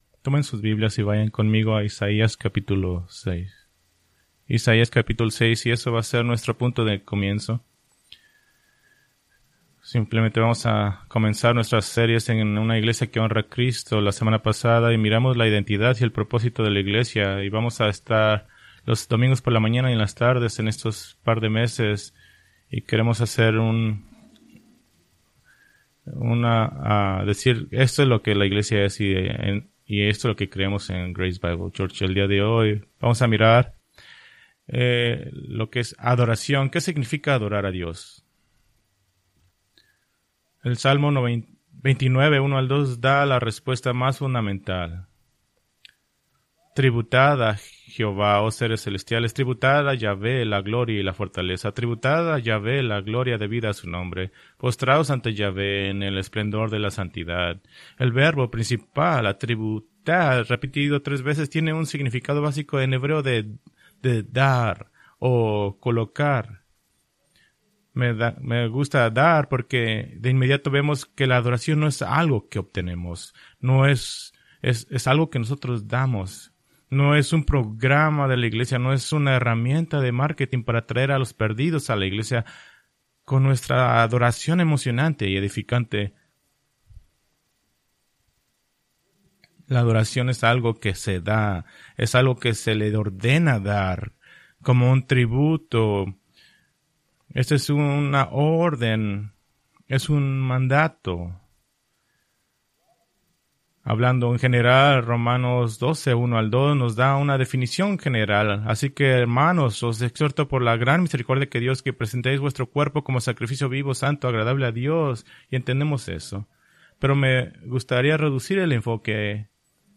Preached March 17, 2024 from Escrituras seleccionadas